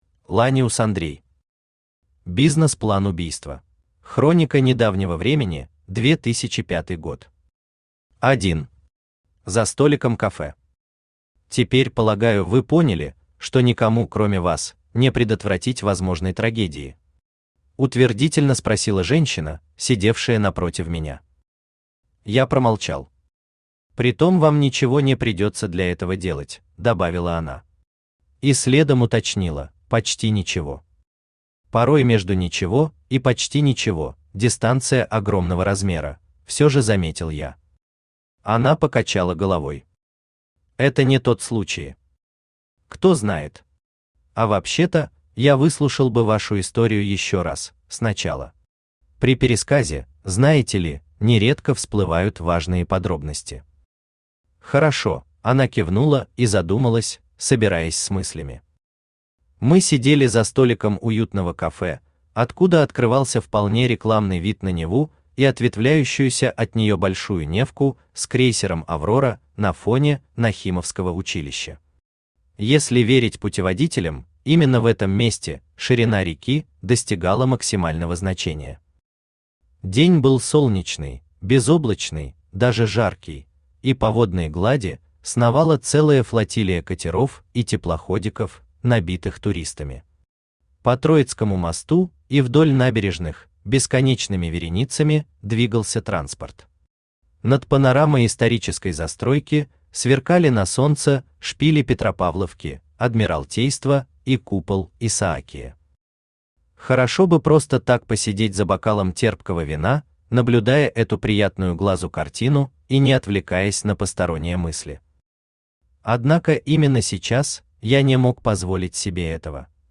Аудиокнига Бизнес-план убийства | Библиотека аудиокниг
Aудиокнига Бизнес-план убийства Автор Ланиус Андрей Читает аудиокнигу Авточтец ЛитРес.